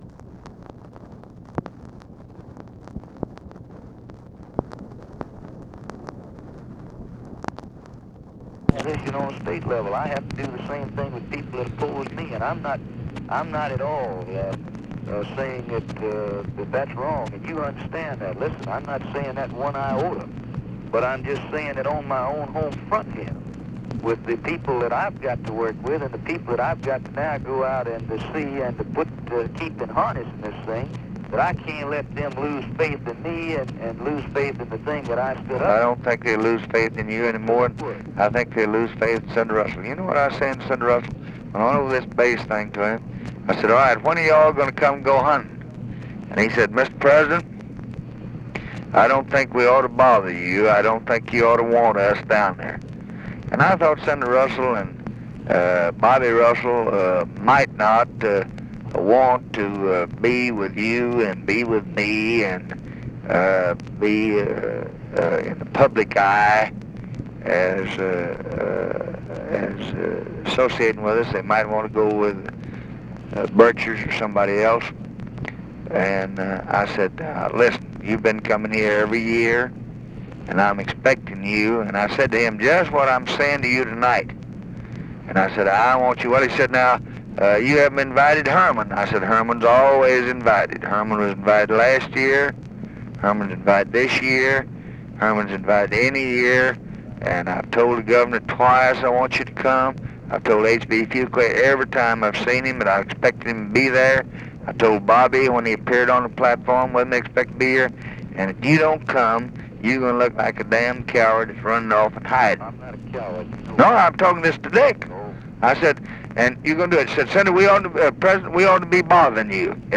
Conversation with CARL SANDERS, November 12, 1964
Secret White House Tapes